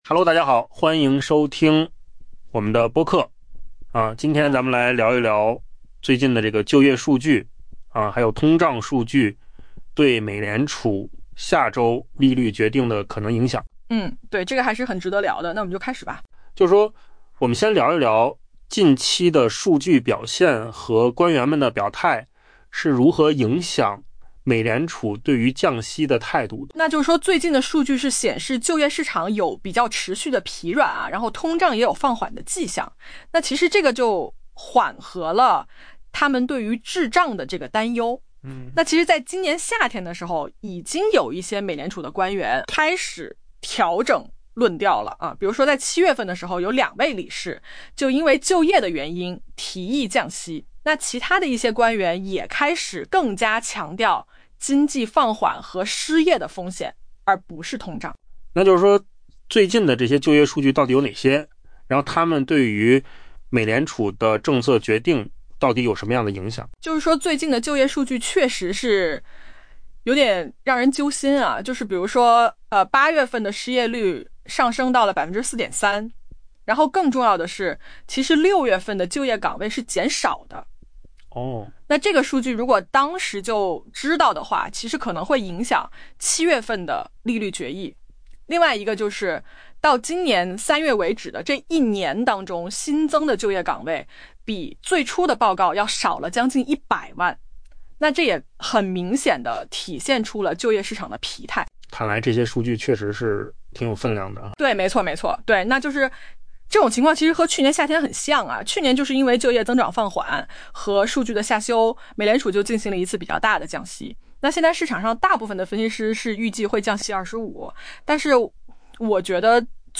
【文章来源：金十数据】AI播客：换个方